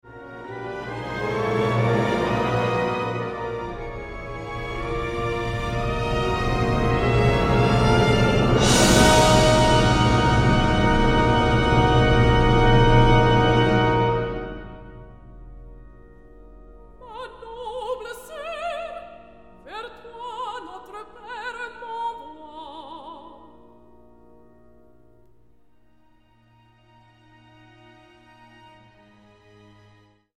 Super Audio CD
World premiere recording.